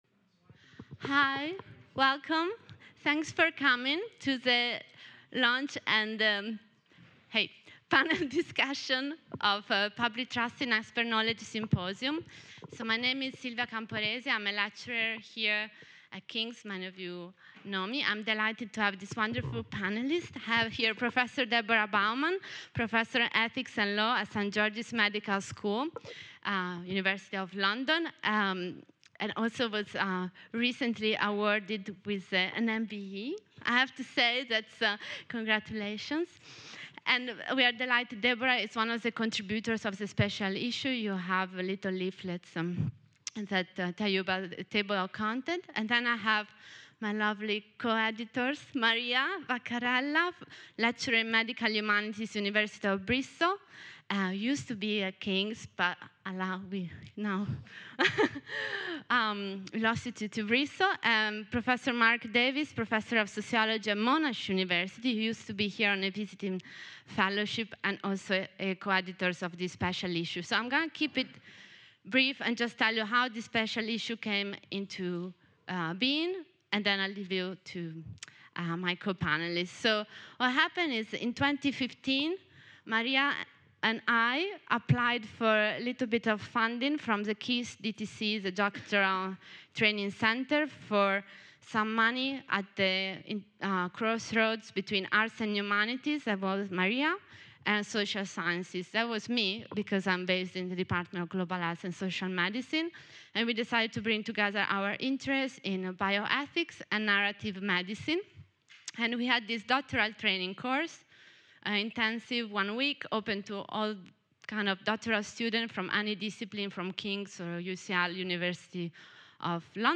On February 8th, 2017, we held at King’s College London the launch of the Journal of Bioethical Inquiry Public Trust in Expert Knowledge Symposium.
The audio-recording of the panel discussion is now available here: